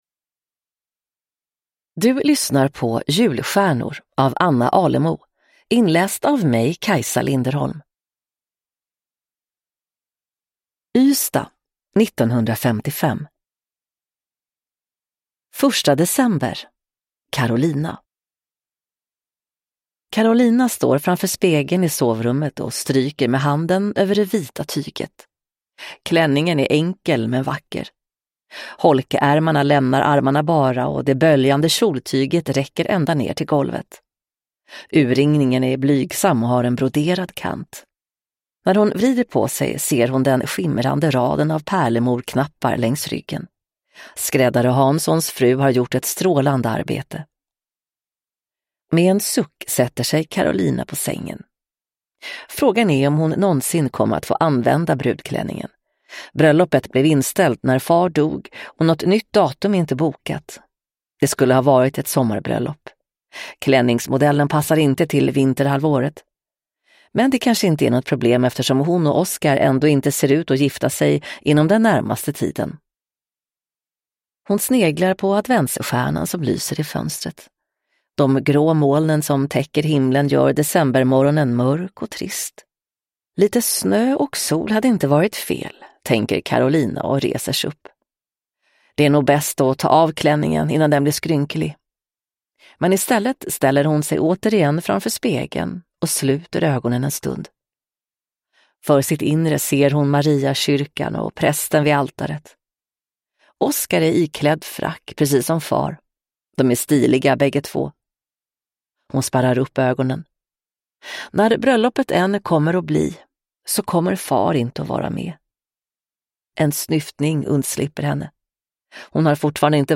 Julstjärnor (ljudbok